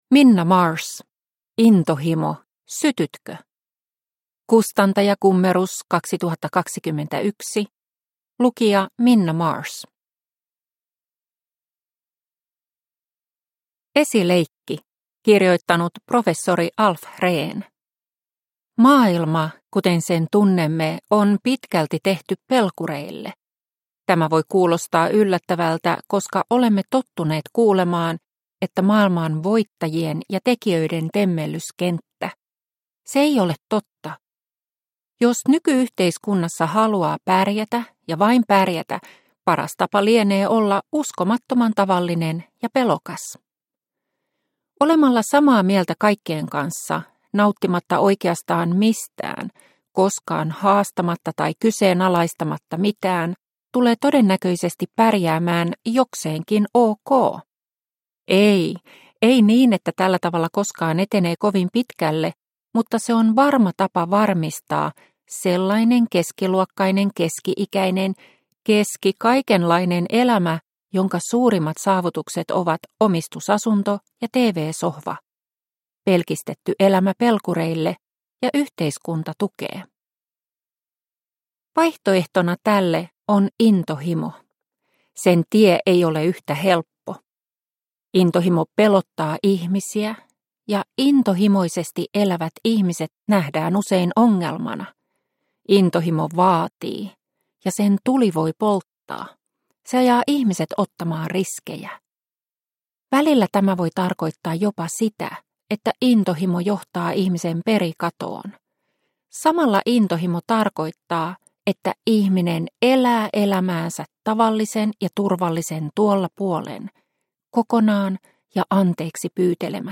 Intohimo – Ljudbok – Laddas ner